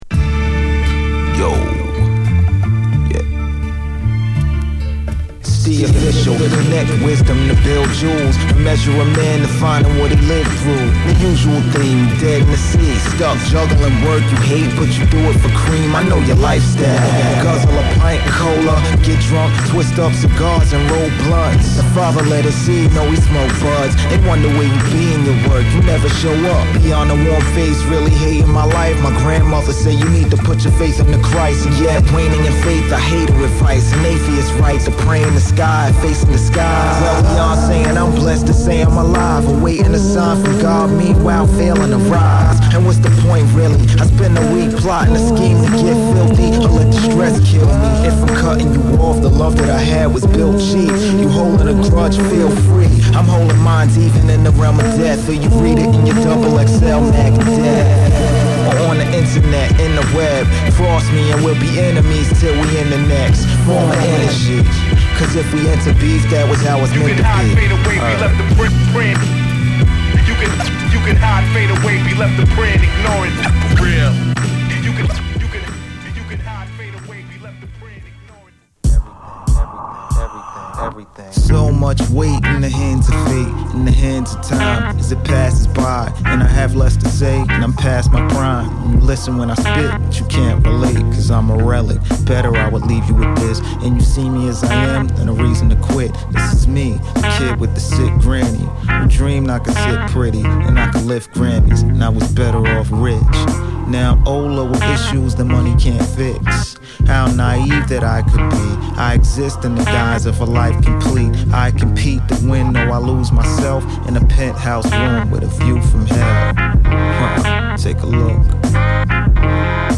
ビート・ラップ共に高いクオリティを保った真摯なスタイルのHip Hopアルバム！